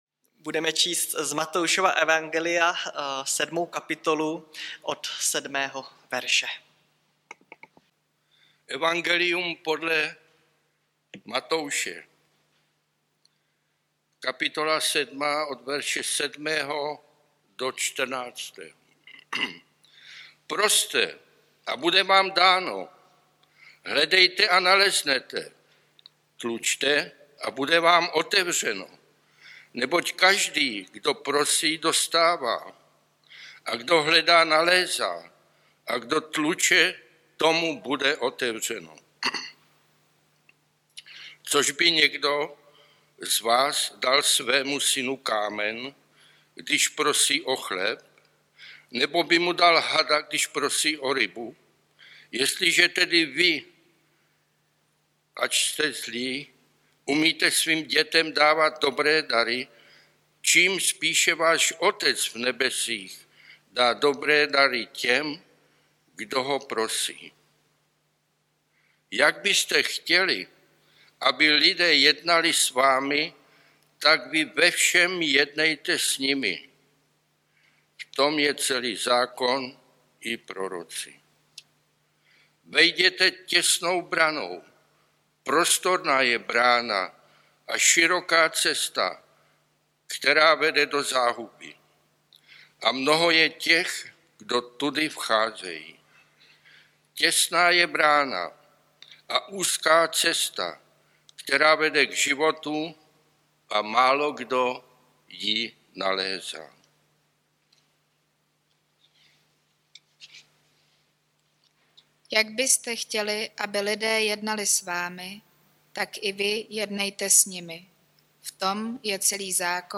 Nedělní kázání – 13.2.2022 Zlaté pravidlo